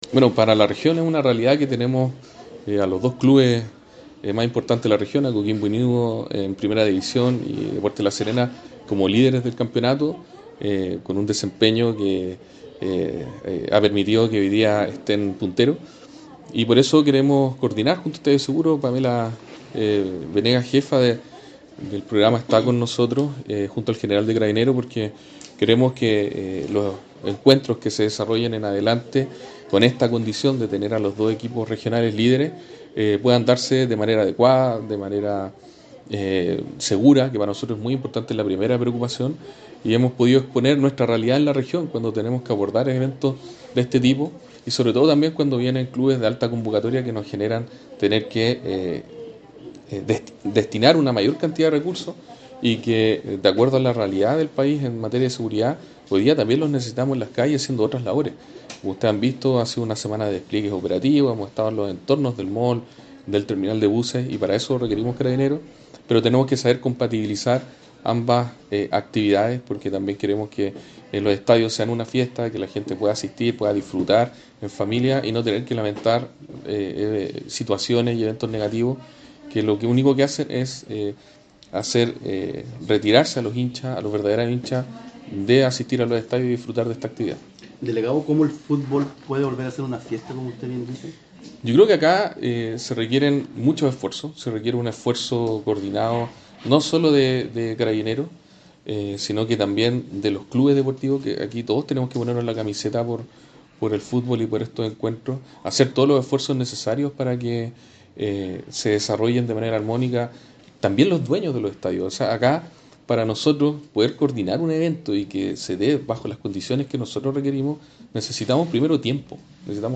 ESTADIO-SEGURO-Delegado-Presidencial-Regional-Galo-Luna-Penna.mp3